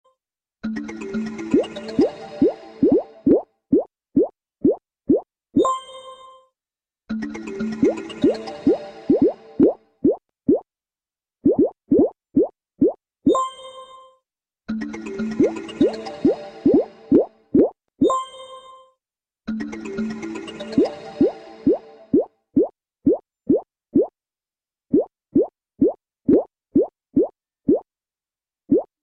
• Качество: 128, Stereo
Забавный сигнал смс